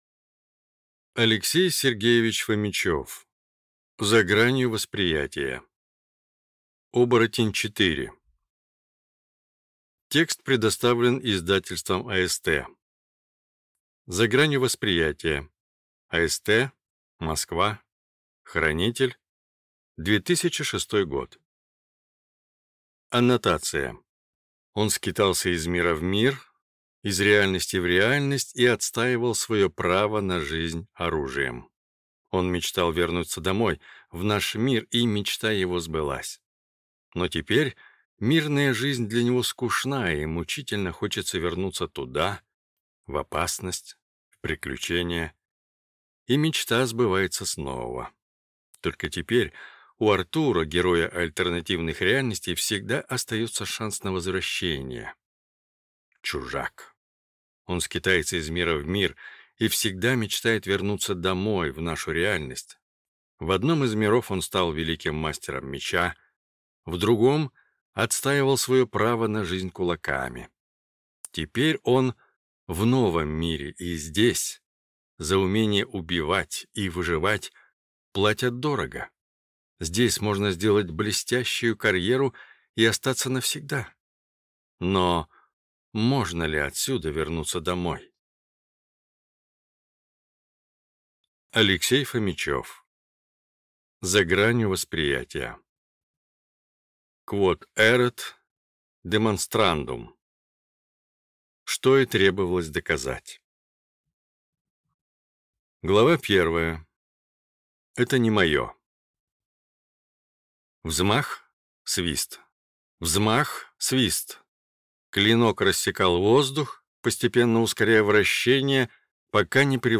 Аудиокнига За гранью восприятия | Библиотека аудиокниг